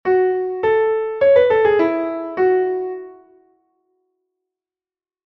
Portato
É a combinación dos signos de articulación de stacatto combinado con ligaduras de expresión.
Para interpretalo hai que separar as notas e acentualas, sempre coa sensación de que están separadas pero sen silencios.
portato.mp3